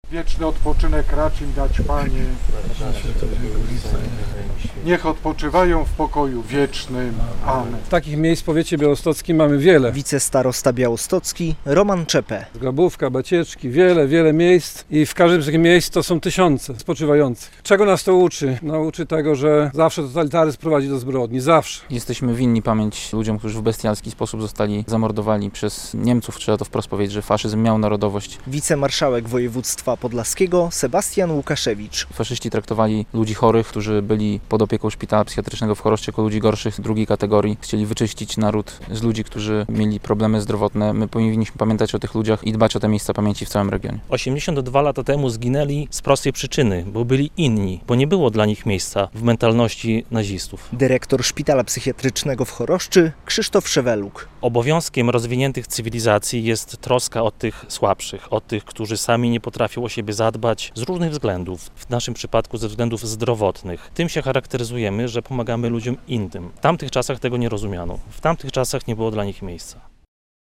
Zgromadzeni pod pomnikiem wspólnie odmówili za zmarłych Anioł Pański.
Musimy podtrzymywać pamięć o tych osobach - mówi wicemarszałek województwa podlaskiego Sebastian Łukaszewicz.